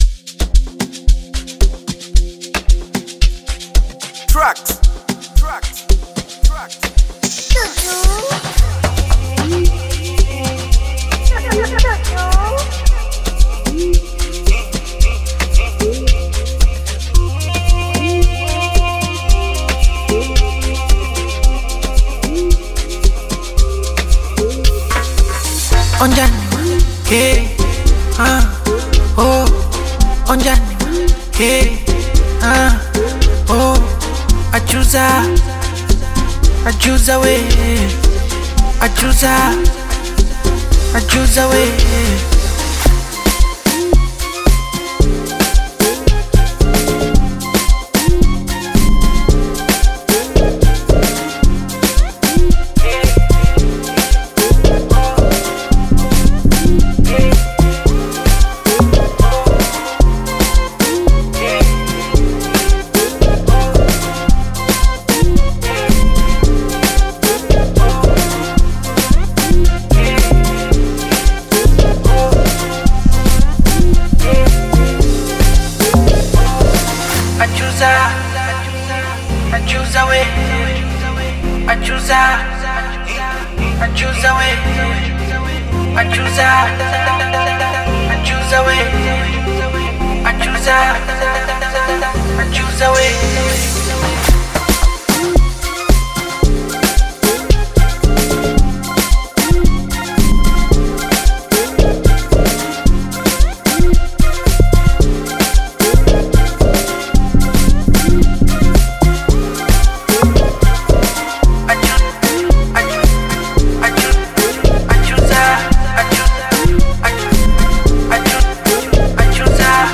Bongo Flava music track
Tanzanian Bongo Flava artists